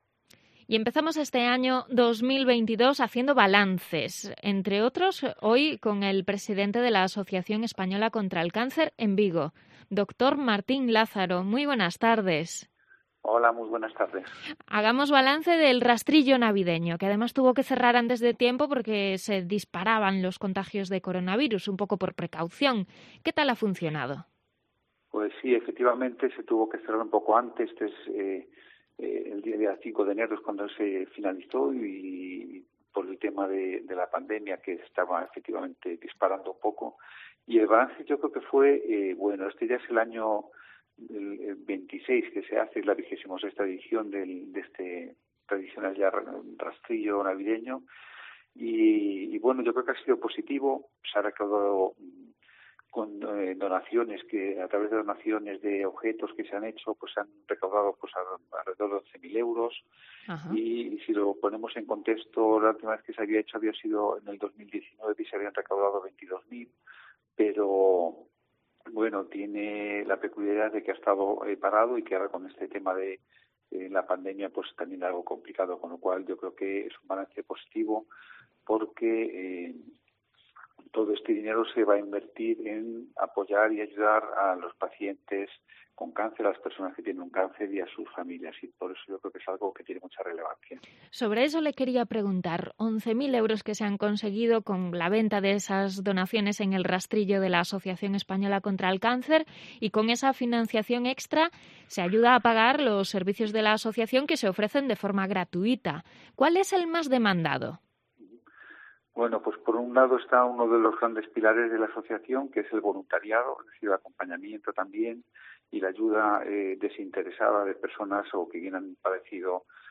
ha dado esa cifra en entrevista en esta emisora.